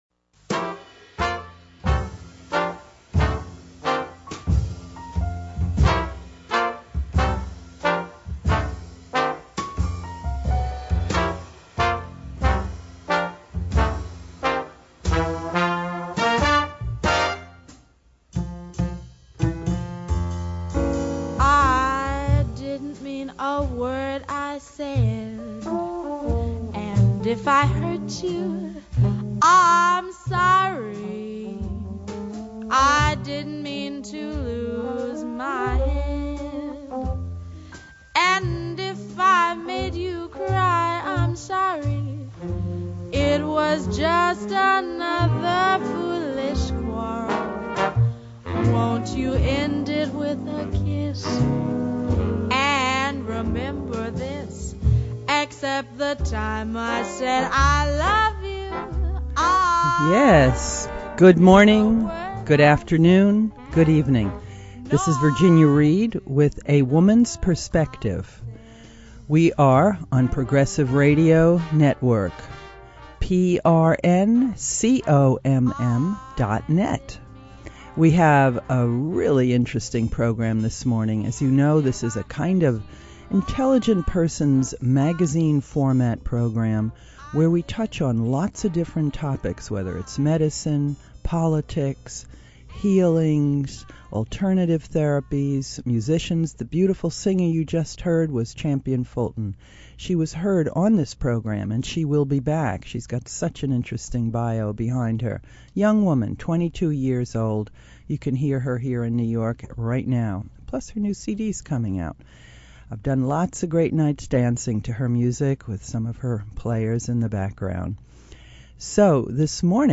on the Radio